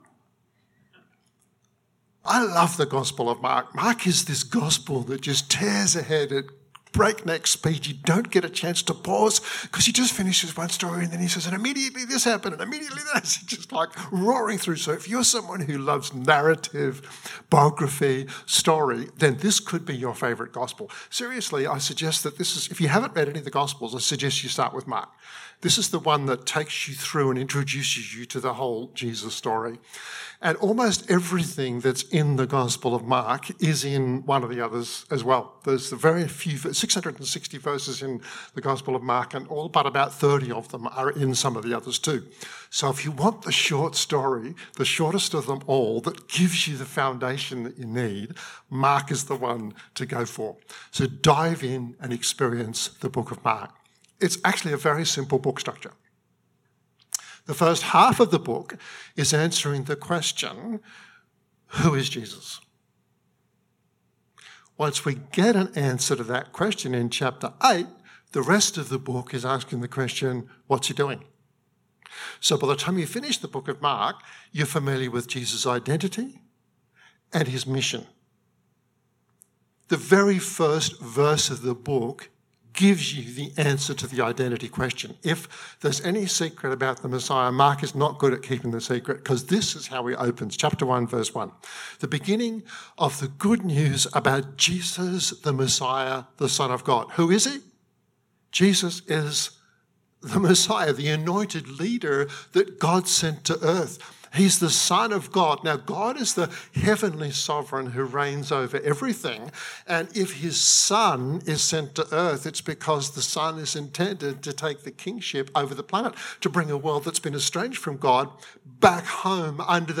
This podcast (24-minutes) is from the message delivered to Riverview Church’s Joondalup campus on 8 August 2021. https